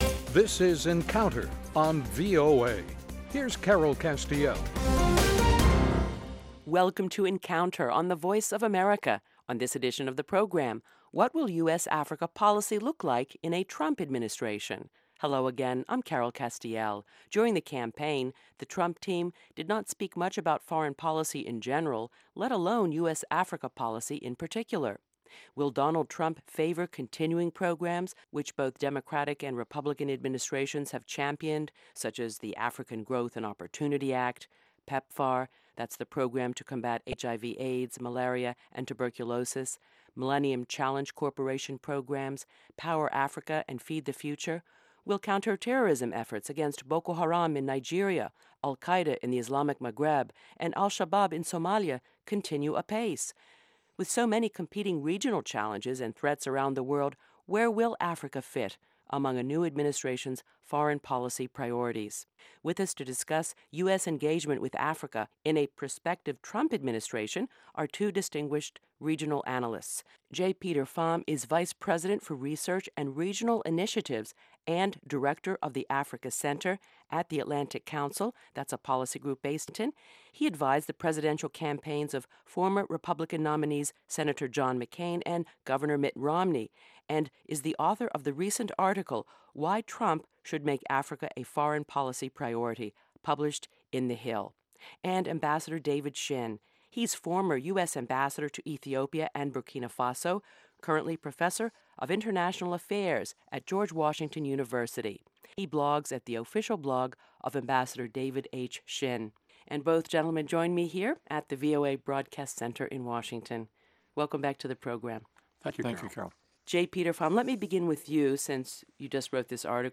Issues that affect our lives and global stability are debated in a free-wheeling, unscripted discussion of fact and opinion.